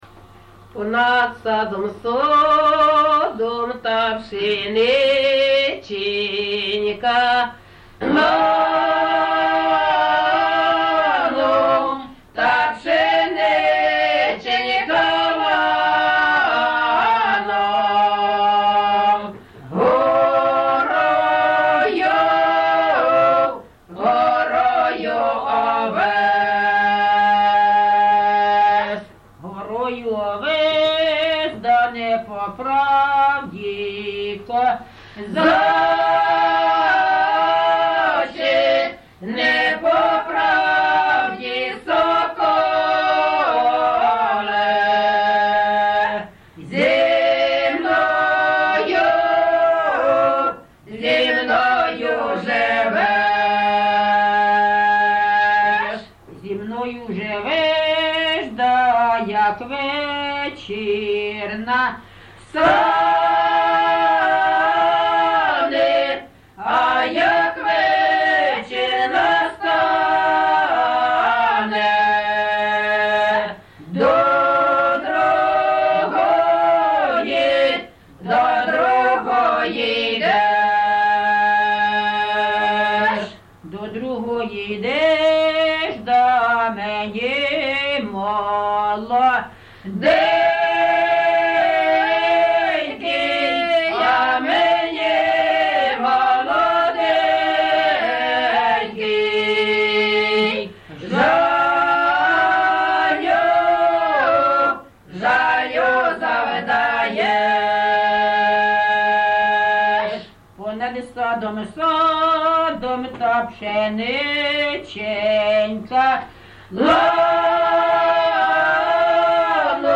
ЖанрПісні з особистого та родинного життя
Місце записус. Почапці, Миргородський район, Полтавська обл., Україна, Полтавщина